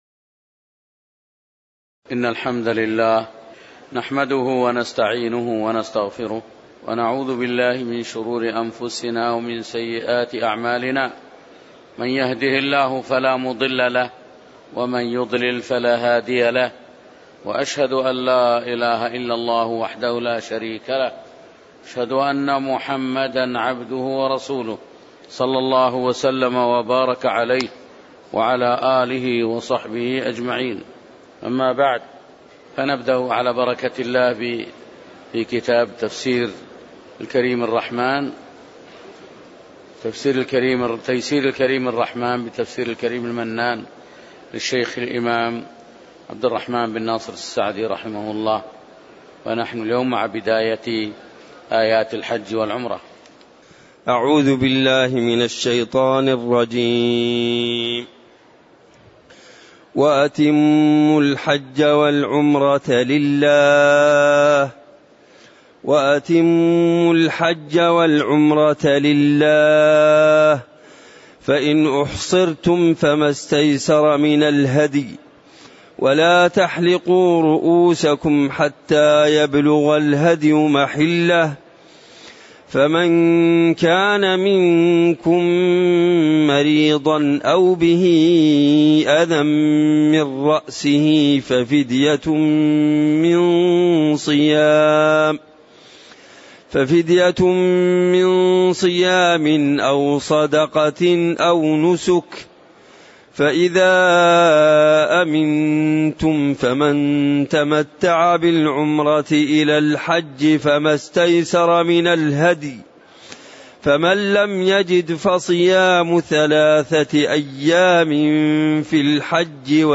تاريخ النشر ٢٠ ذو القعدة ١٤٣٨ هـ المكان: المسجد النبوي الشيخ